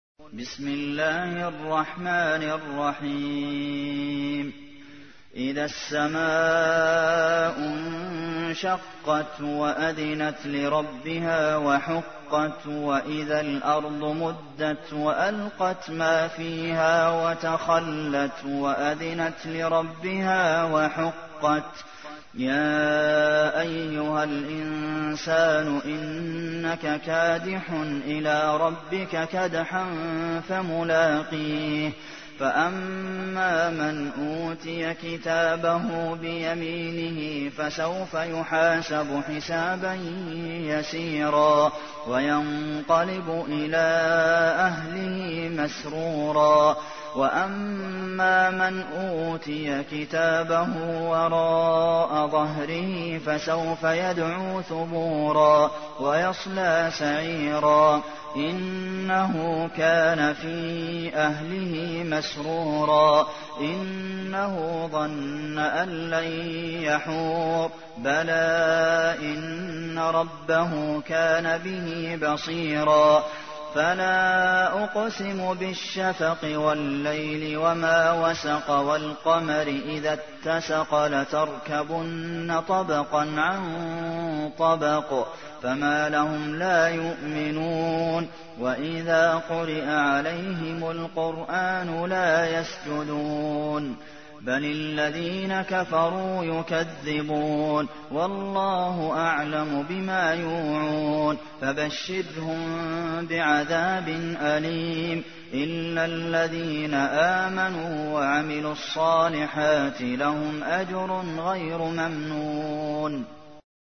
تحميل : 84. سورة الانشقاق / القارئ عبد المحسن قاسم / القرآن الكريم / موقع يا حسين